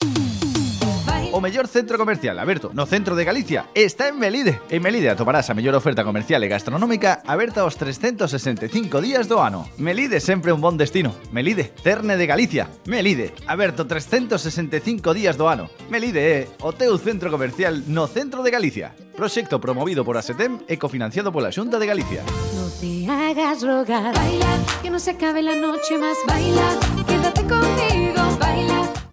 Cuña de publicidade en Verbena FM